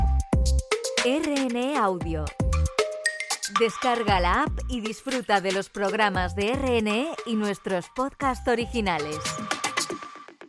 Indicatiu del portal a Internet RNE Audio